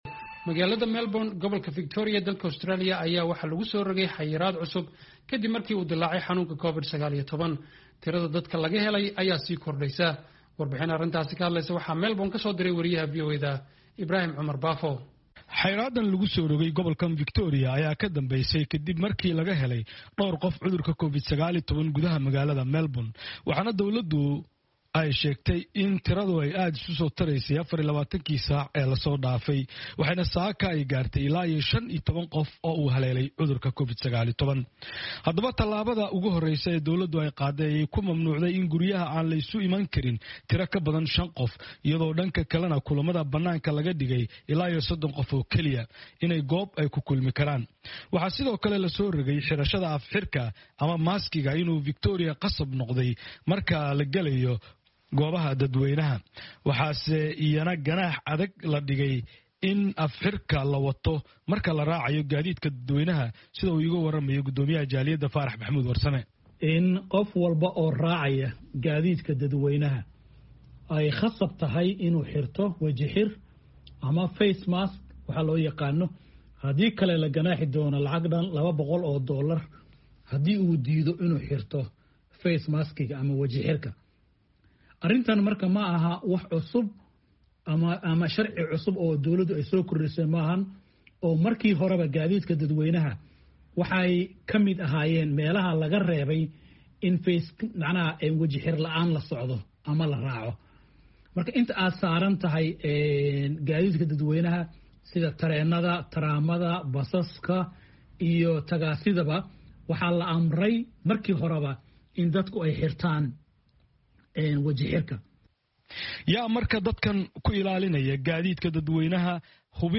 Warbixnta waxaa magaalda Melbourne ka soo diray